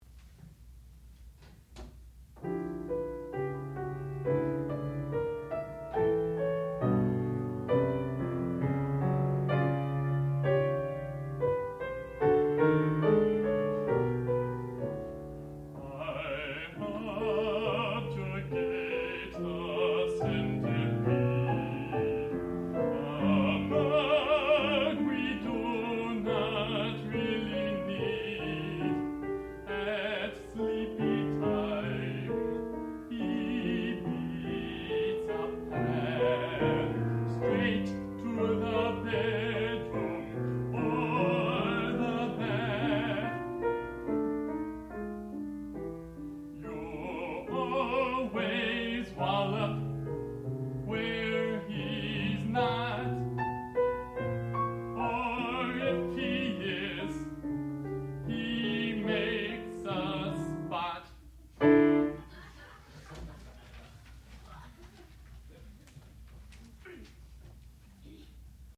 sound recording-musical
classical music
soprano
piano
baritone